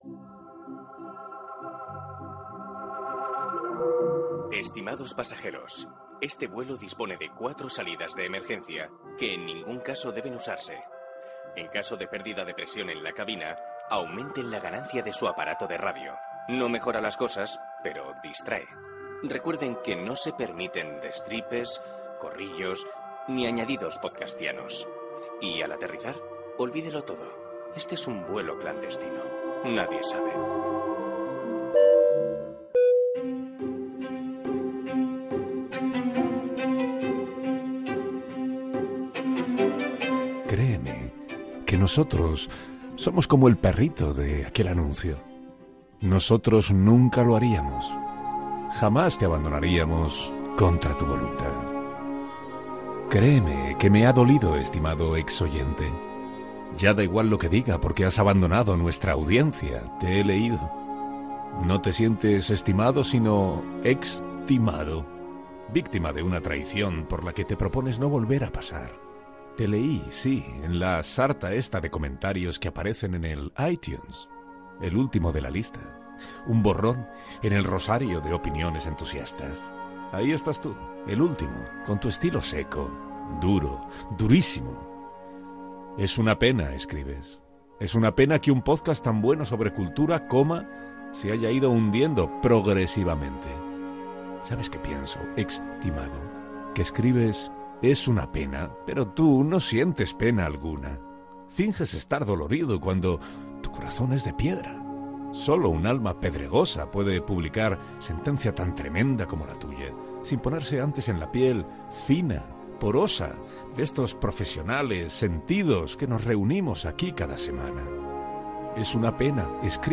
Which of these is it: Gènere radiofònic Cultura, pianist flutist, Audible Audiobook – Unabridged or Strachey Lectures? Gènere radiofònic Cultura